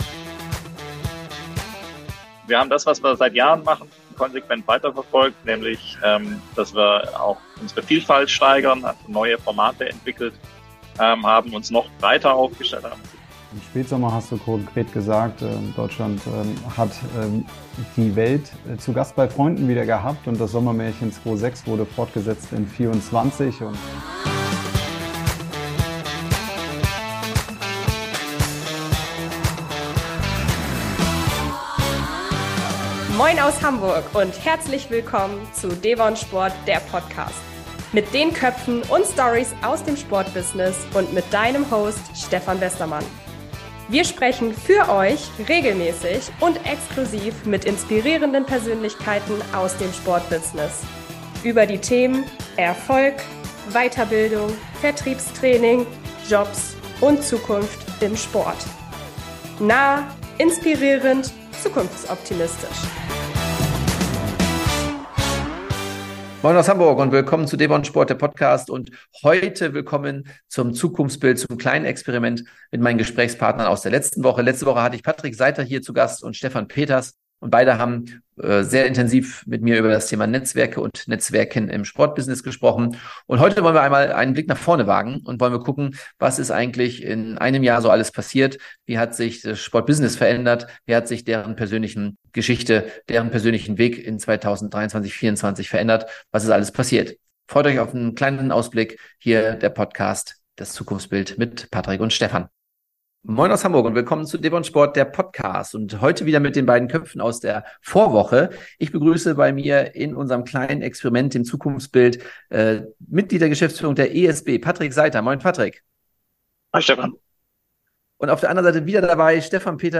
heute in Teil 2 des Interviews ihre Vision für die Zukunft ihrer